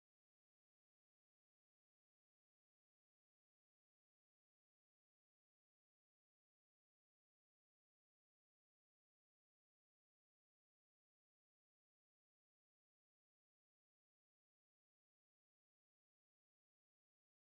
Tonart: D-Dur
Taktart: 4/4
Tonumfang: große Sexte